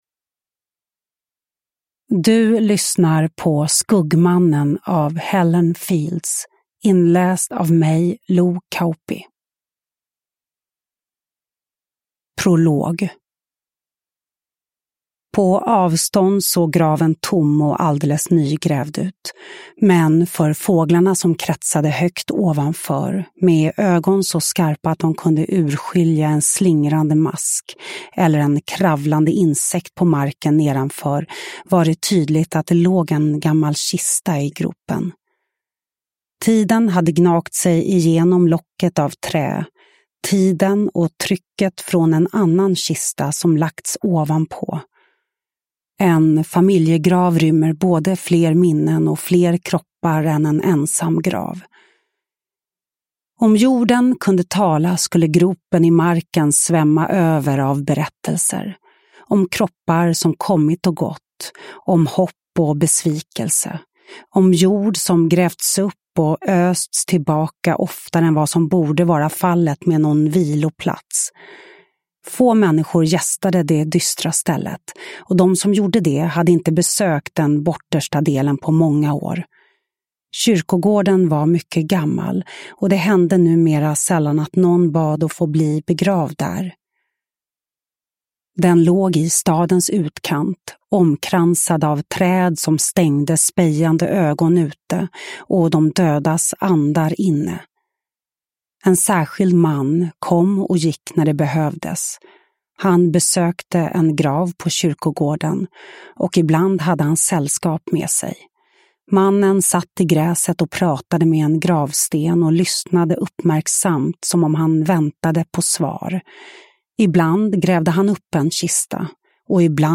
Skuggmannen – Ljudbok – Laddas ner
Uppläsare: Lo Kauppi